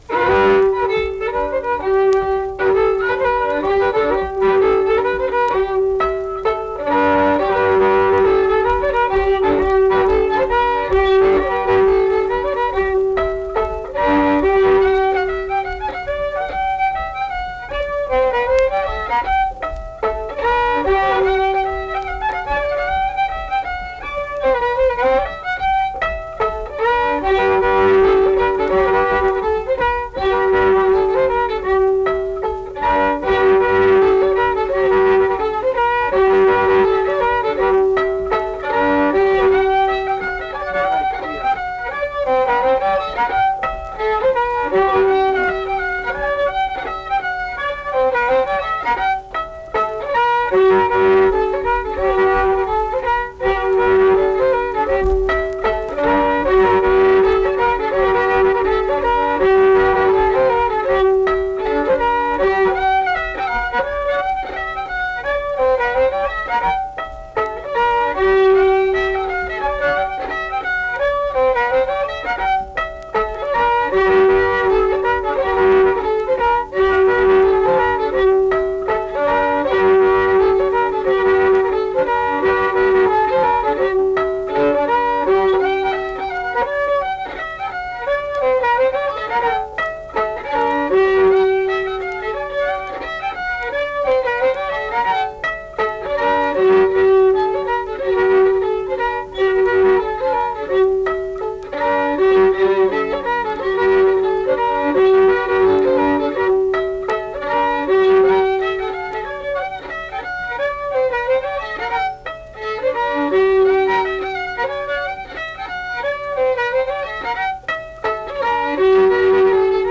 Accompanied guitar and unaccompanied fiddle music performance
Instrumental Music
Fiddle
Mill Point (W. Va.), Pocahontas County (W. Va.)